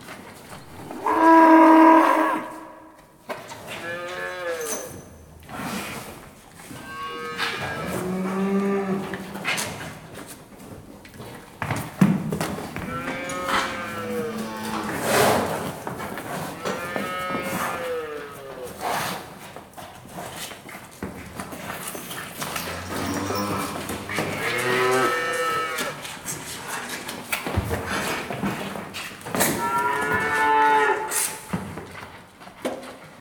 cowbarn.ogg